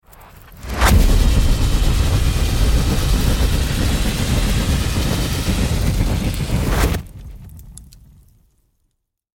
دانلود صدای آتش 10 از ساعد نیوز با لینک مستقیم و کیفیت بالا
جلوه های صوتی
برچسب: دانلود آهنگ های افکت صوتی طبیعت و محیط دانلود آلبوم صدای شعله های آتش از افکت صوتی طبیعت و محیط